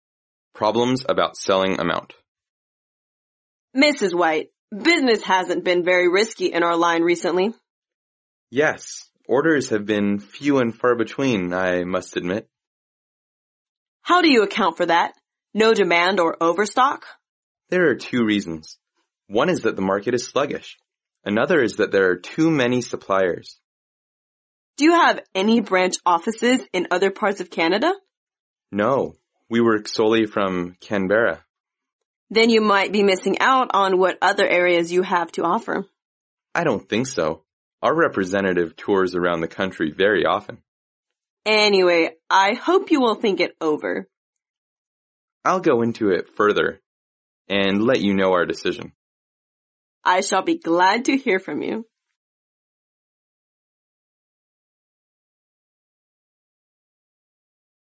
在线英语听力室外贸英语话题王 第109期:销售问题的听力文件下载,《外贸英语话题王》通过经典的英语口语对话内容，学习外贸英语知识，积累外贸英语词汇，潜移默化中培养英语语感。